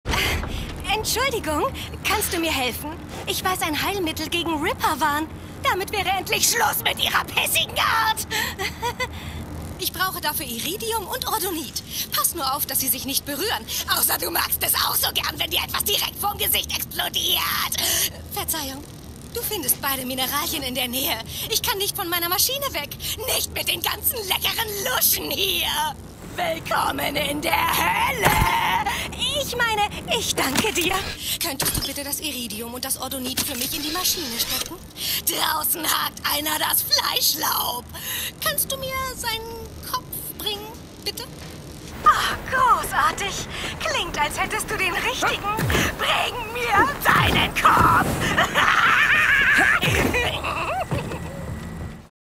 Prof. Ambreleigh (Wissenschaftlerin)